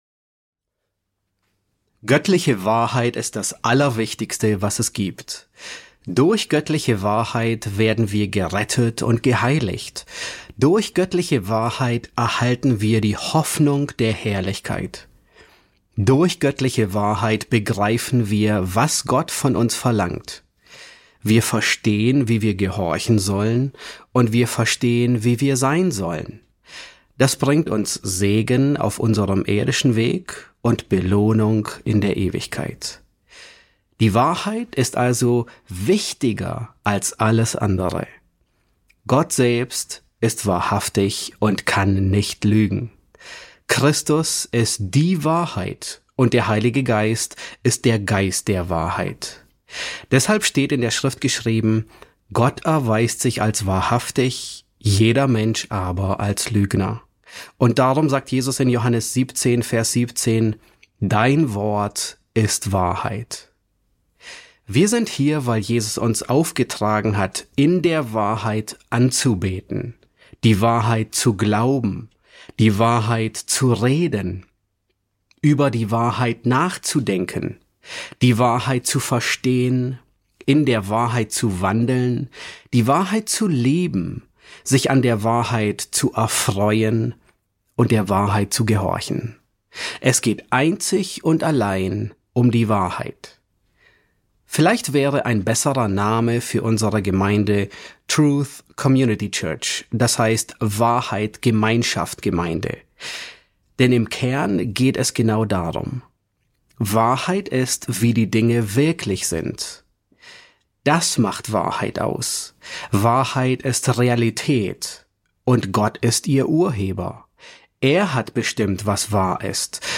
S8 F1 | Die Herrlichkeit des Evangeliums ~ John MacArthur Predigten auf Deutsch Podcast